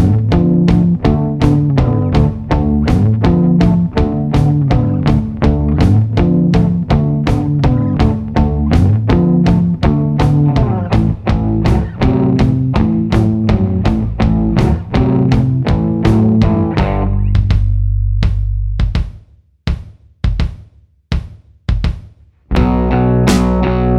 no Backing Vocals with intro bar Pop (1960s) 4:13 Buy £1.50